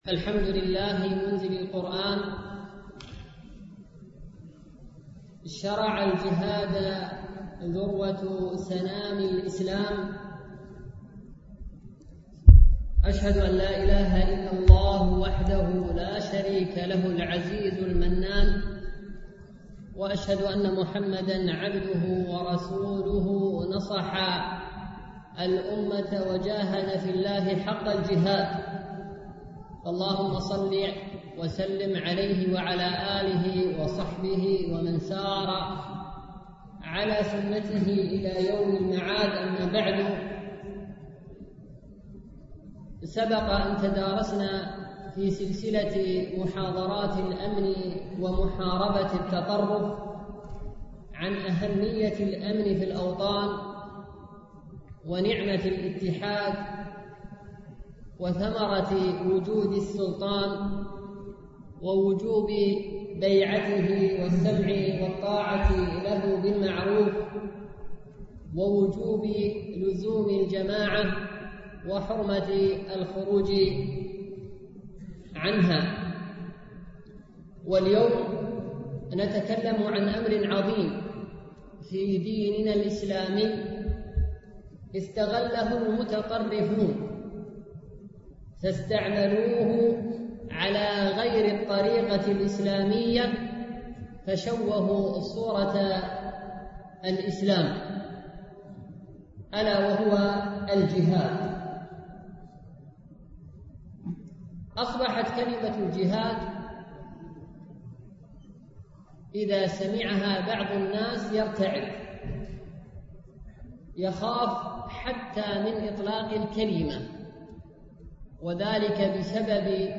سلسلة محاضرات الأمن ومحاربة التطرف